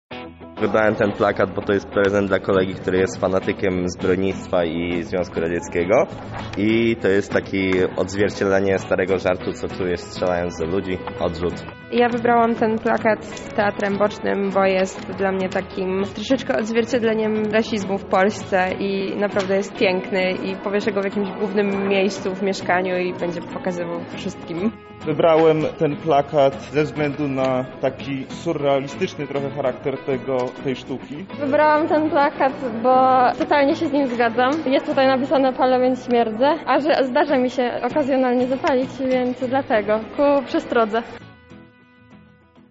Goście Chatki Żaka zdradzili nam co najbardziej przykuło ich uwagę:
plakaty relacja